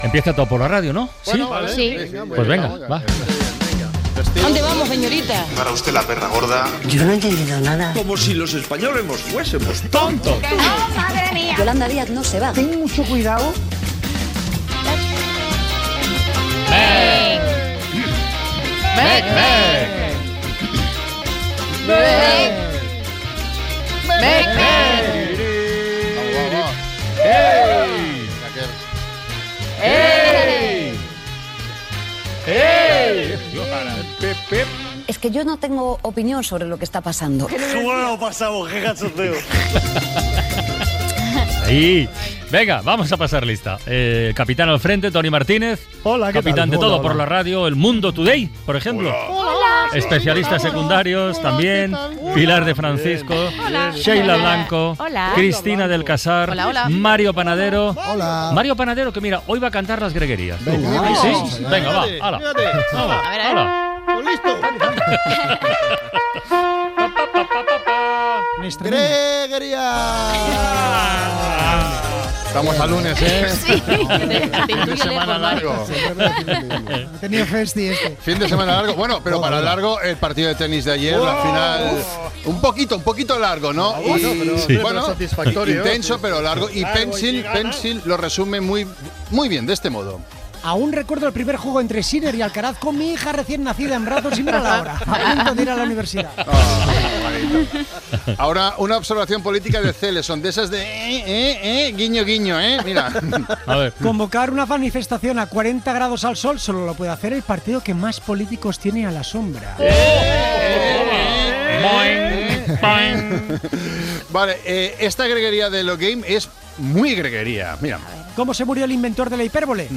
88e60d5daca53be370d385c7d862c196d2a9381c.mp3 Títol Cadena SER Emissora Ràdio Barcelona Cadena SER Titularitat Privada estatal Nom programa La ventana Descripció Secció "Todo por la radio". Presentació dels col·laboradors de la secció, comentaris inicials, les notícies de "El mundo today", tema musical, efemèride, el fiscal general de l'Estat Gènere radiofònic Entreteniment